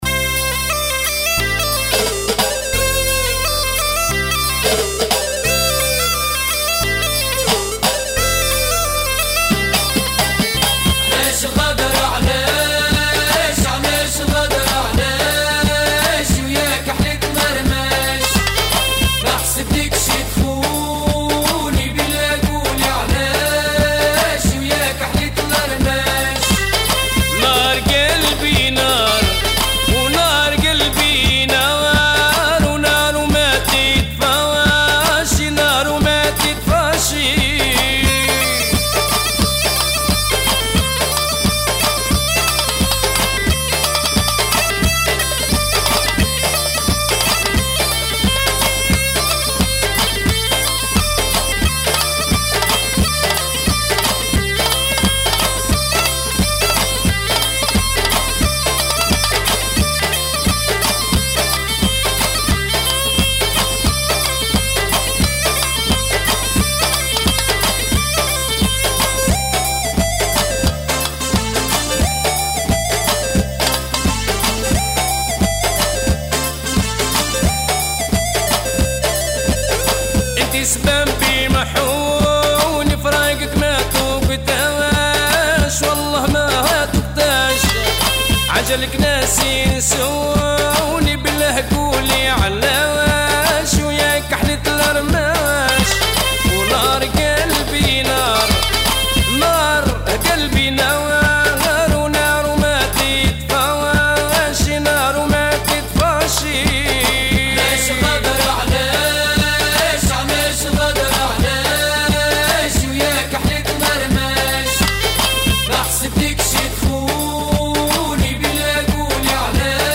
Bienvenue au site des amateurs de Mezoued Tunisien